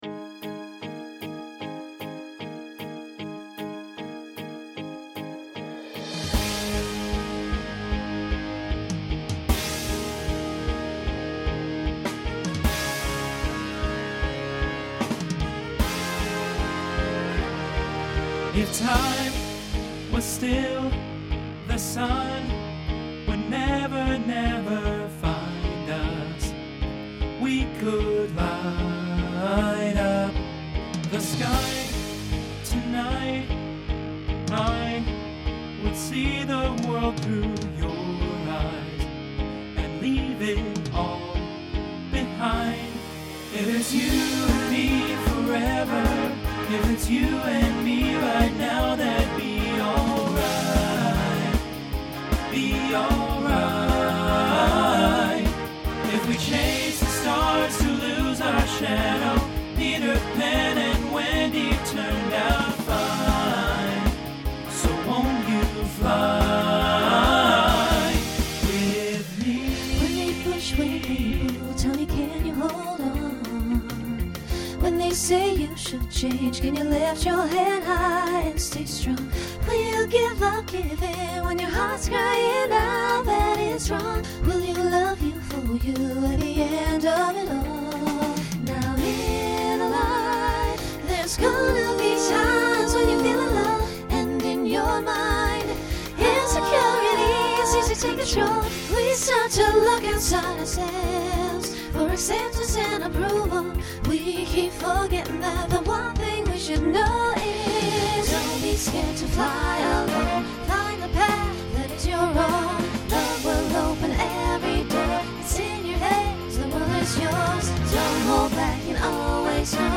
TTB/SSA
Voicing Mixed
Genre Pop/Dance